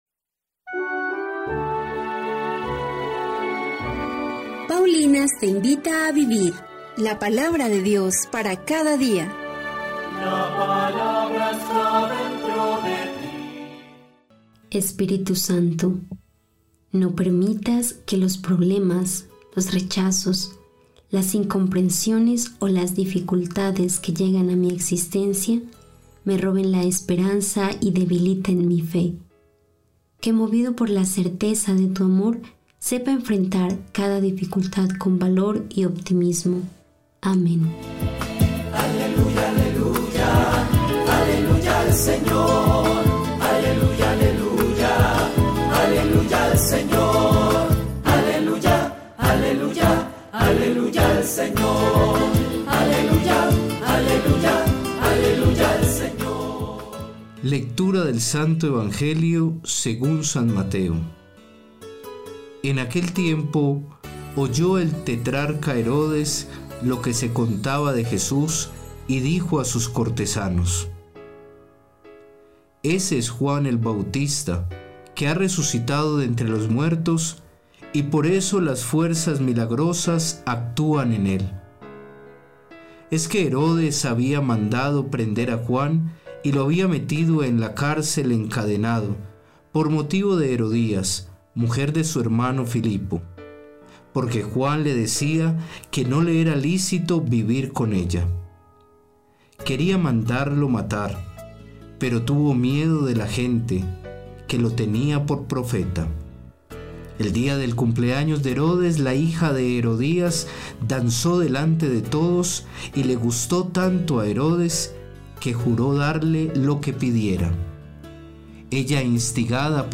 Primera Lectura